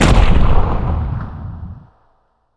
attack3_2.wav